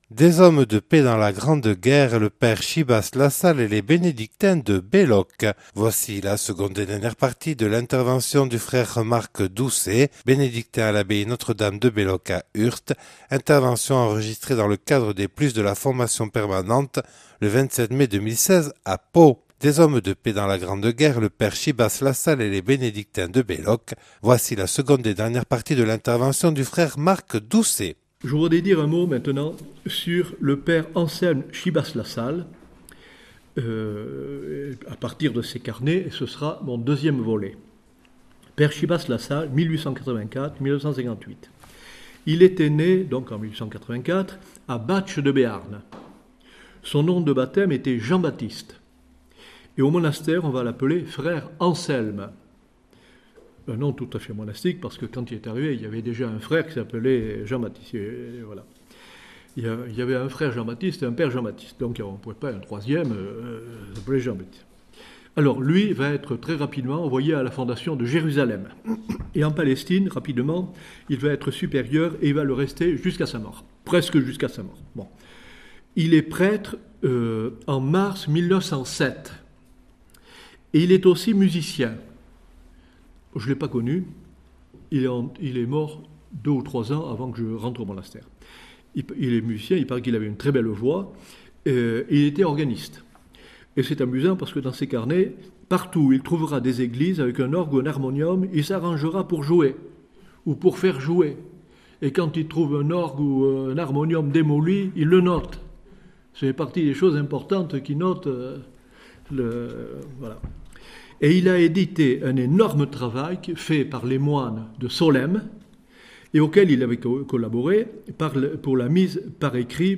Deuxième et dernière partie de la conférence
(Enregistrée à Pau le 27 mai 2016).